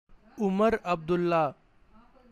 pronunciation
Omar_Abdullah_Pronunciation.ogg.mp3